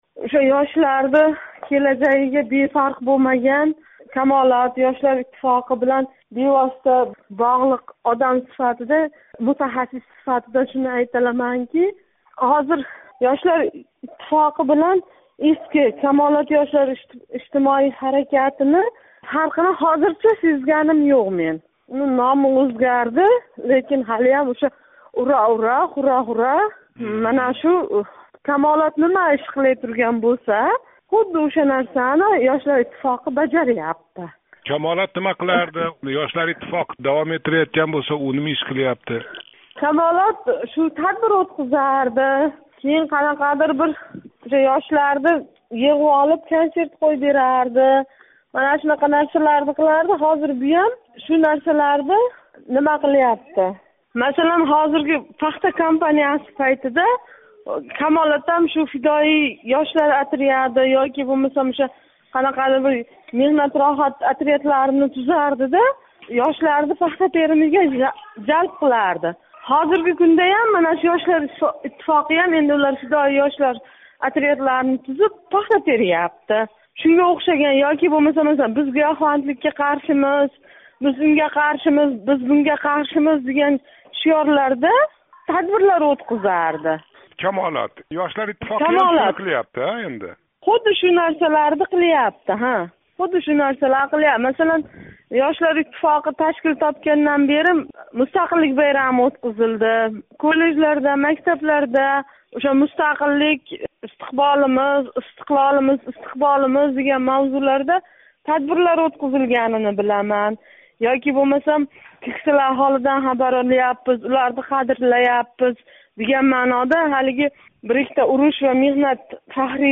Совет комсомолининг меросхўрларидан бири “Камолот” ва унинг ўрнида ташкил қилинган Ёшлар иттифоқи фаолиятини қиёслаб ўрганиб келаётган собиқ камолотчи Озодлик билан суҳбатда, янги ташкилот ишида фавқулодда янгилик кўрмаётганини айтди.